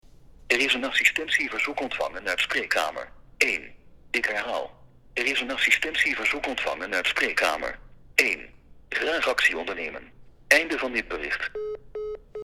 dit spraakbericht is duidelijk te horen waar en door wie het alarm is gemaakt.
Spraakbericht-voorbeeld.mp3